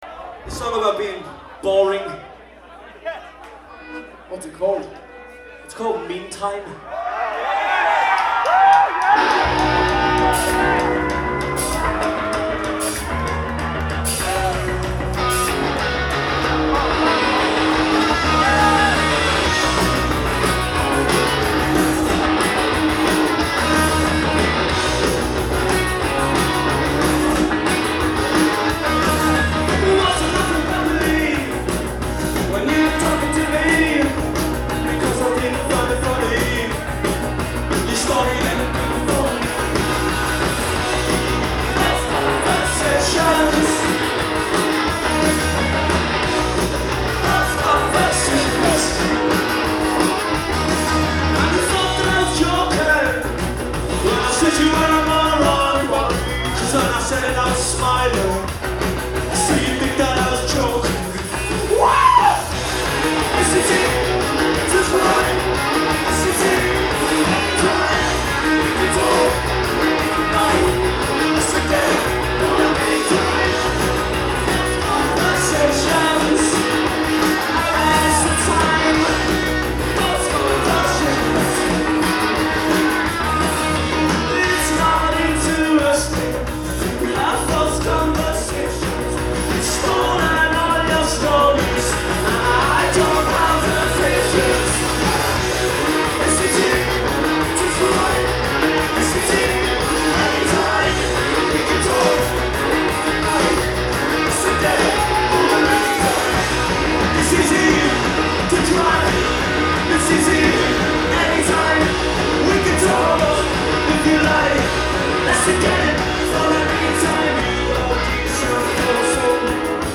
Live at The Paradise